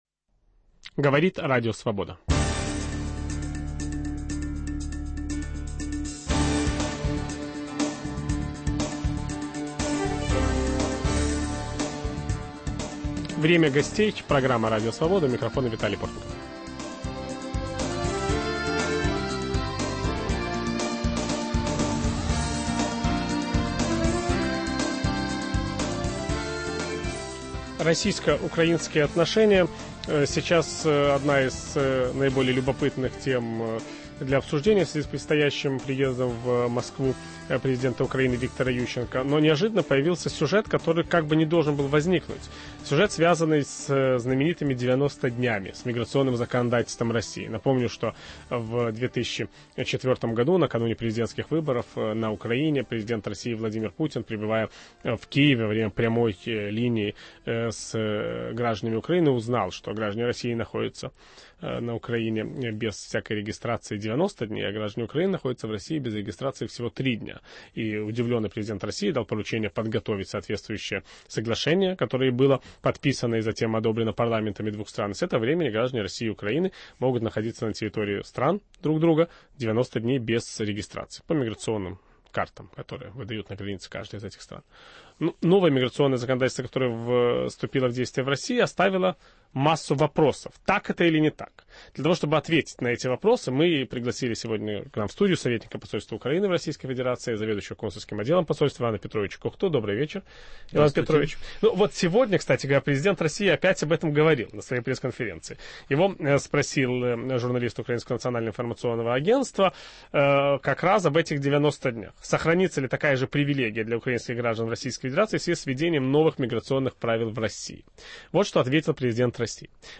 Новое российское миграционное законодательство и украинцы. В студии - генеральный консул Украины в России Иван Кухта.